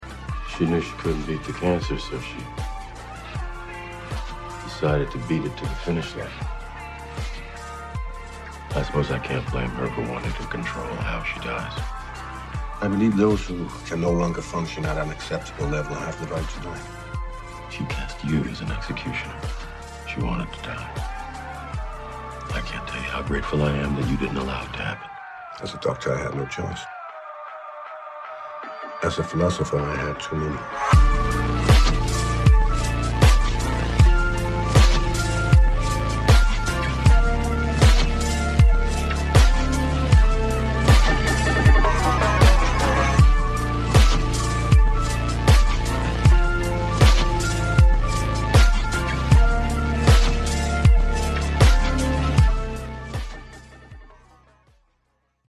Instrumental slowed